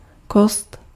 Ääntäminen
IPA: [ka.nɔ̃]